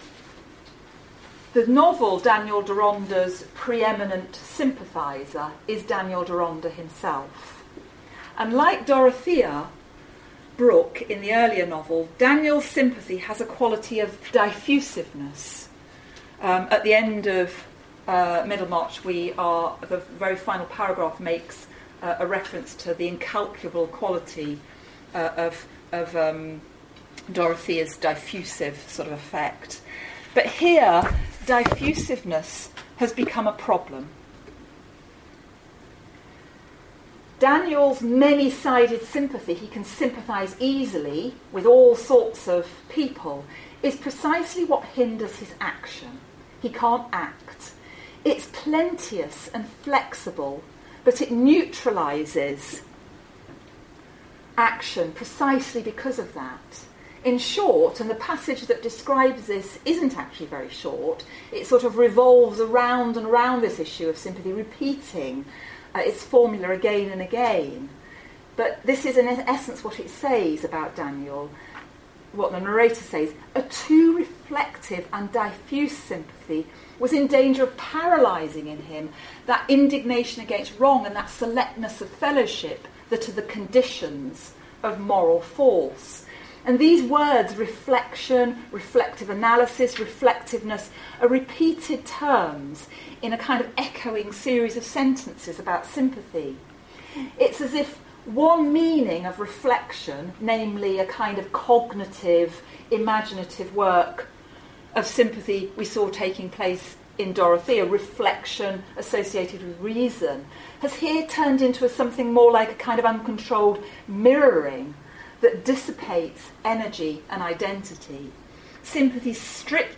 English Lecture 12th of March 2015 Part 1.mp3 (23.5 Mb) Audio